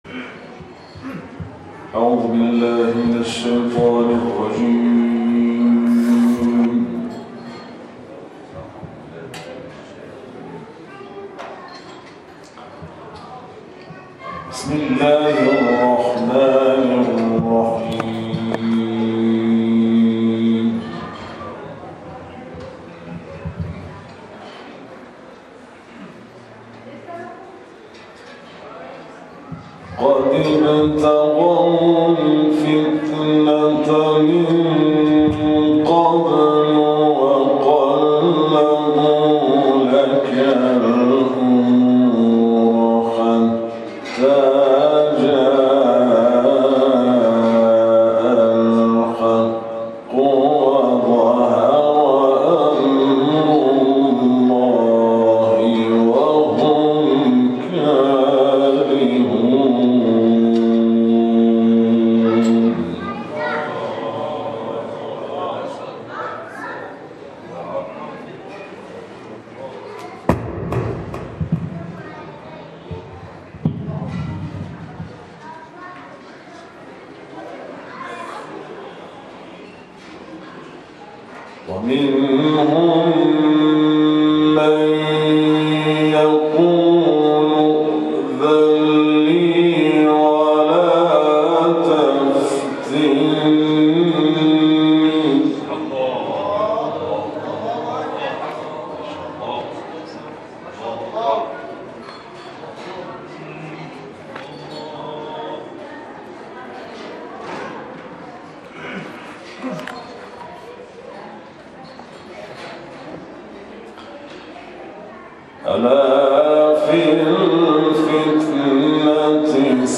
تلاوت آیات 48 تا 60 سوره مبارکه توبه